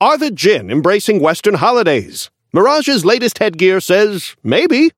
Newscaster voice line - Are the Djinn embracing western holidays? Mirage's latest headgear says... "Maybe"?
Newscaster_seasonal_mirage_unlock_01_alt_01.mp3